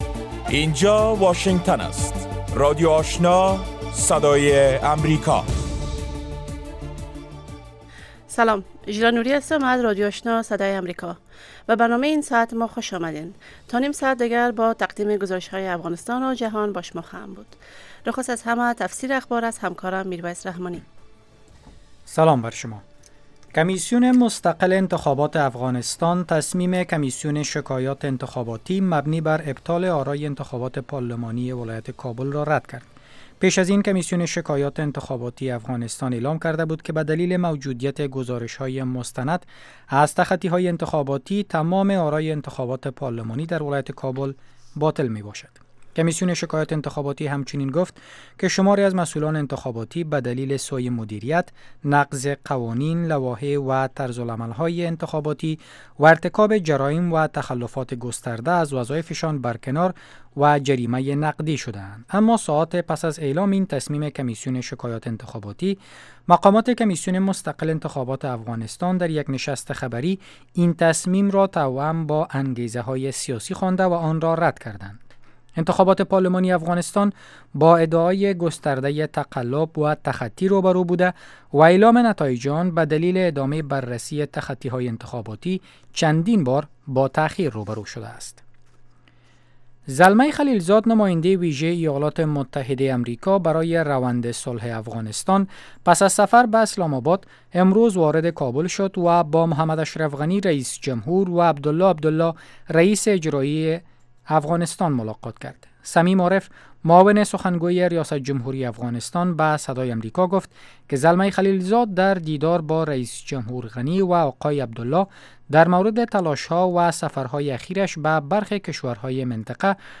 نخستین برنامه خبری شب
در برنامه خبری شامگاهی، خبرهای تازه و گزارش های دقیق از سرتاسر افغانستان، منطقه و جهان فقط در سی دقیقه پیشکش می شود.